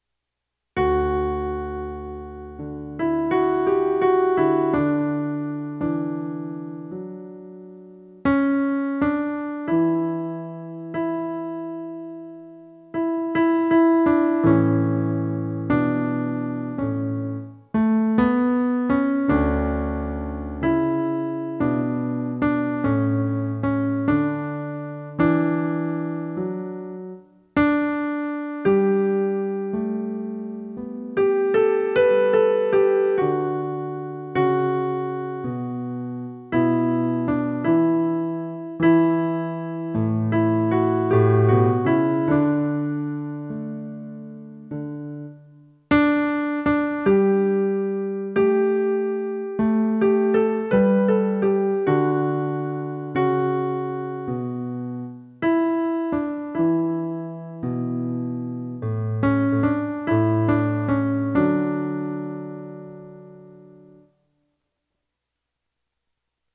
Lord, You Have Come - Alto
LordYouHaveCome_Alto.mp3